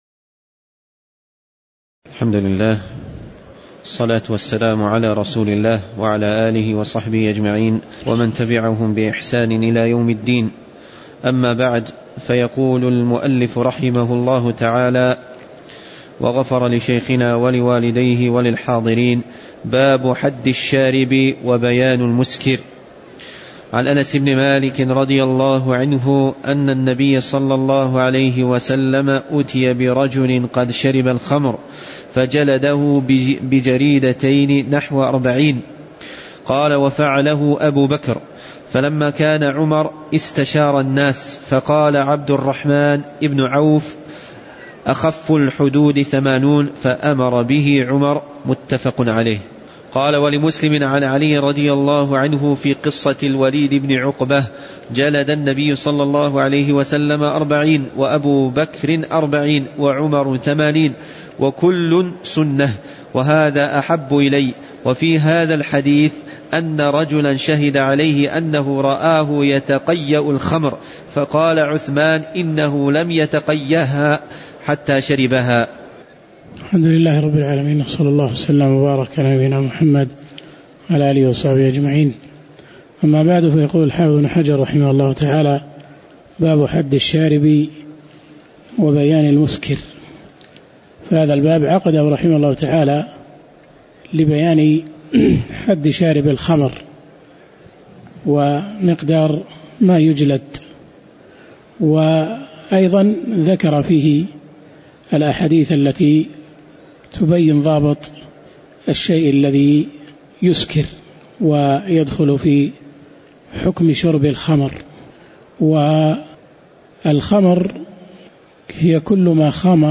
تاريخ النشر ٧ صفر ١٤٤٠ هـ المكان: المسجد النبوي الشيخ